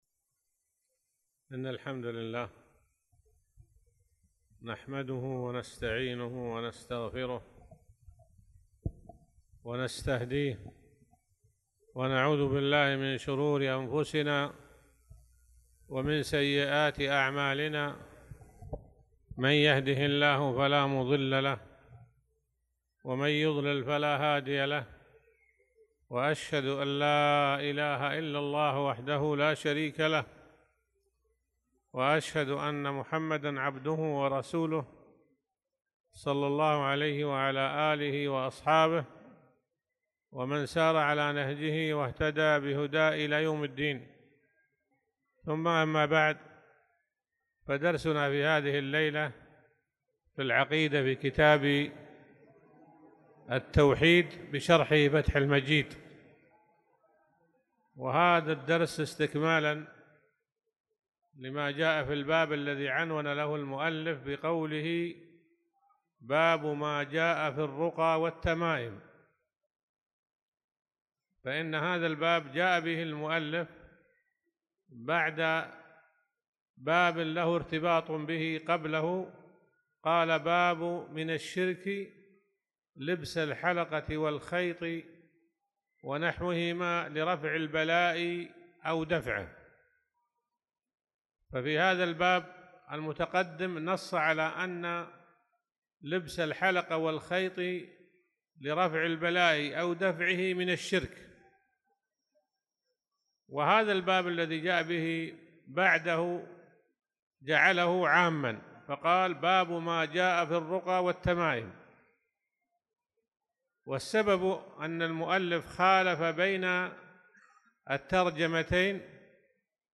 تاريخ النشر ٢١ شوال ١٤٣٧ هـ المكان: المسجد الحرام الشيخ